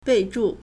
备注 (備註) bèizhù
bei4zhu4.mp3